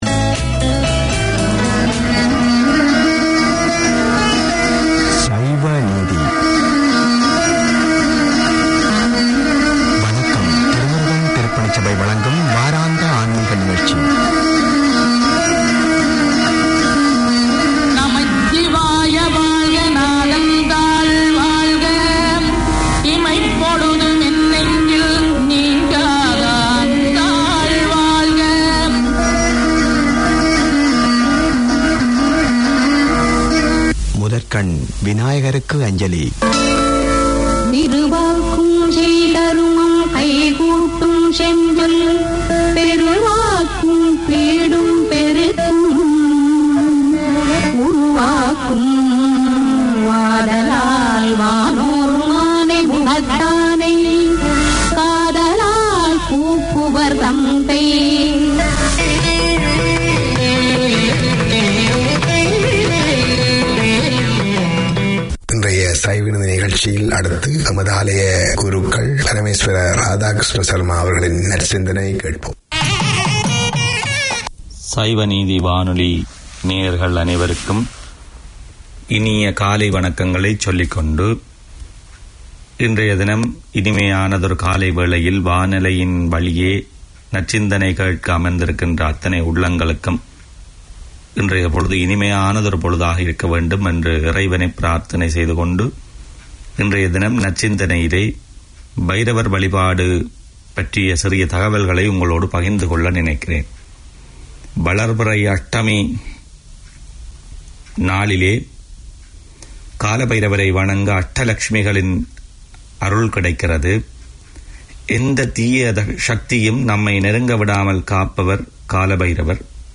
An outreach of NZ Thirumurugan Temple Society, Saiva Neethi airs music, songs, devotions, temple news and children's segments for the Tamil Hindu community.